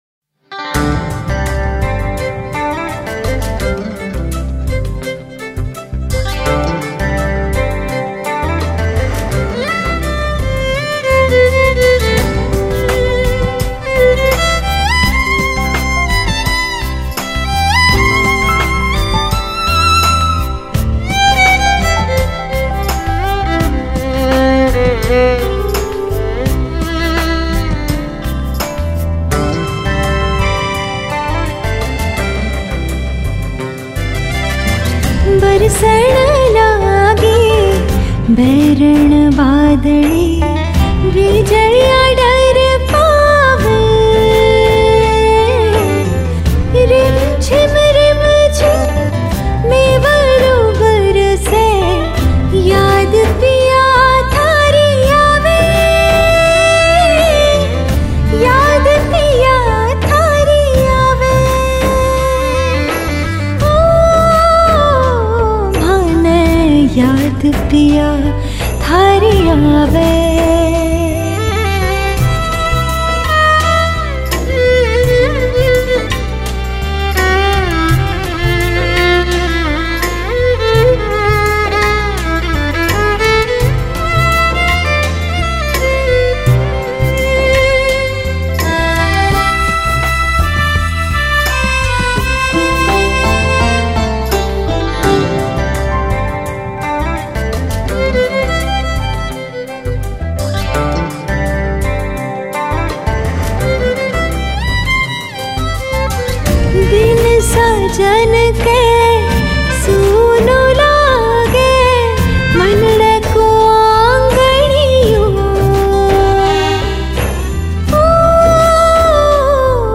[Folk]